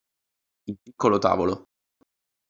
Read more (masculine) table, board, desk (masculine) board, panel Frequency B1 Hyphenated as tà‧vo‧lo Pronounced as (IPA) /ˈta.vo.lo/ Etymology From Latin tabula, with a change in gender and inflection.